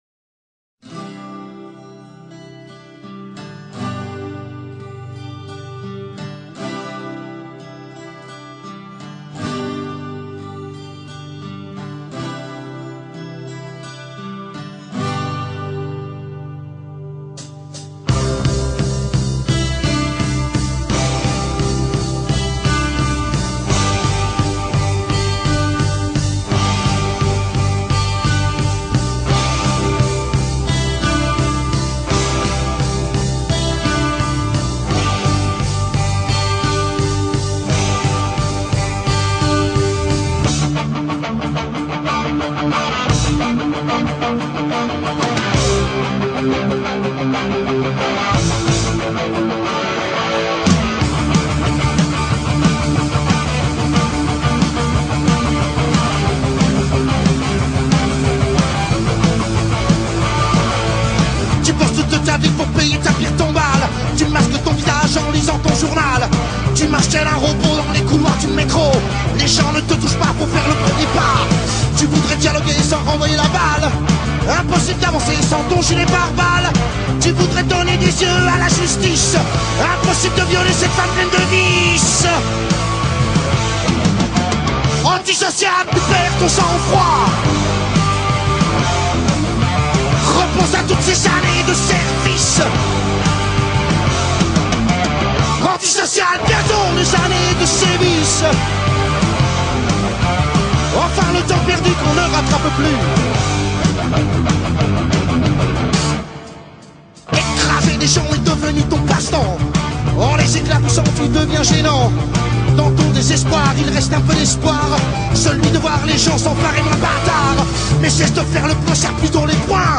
French version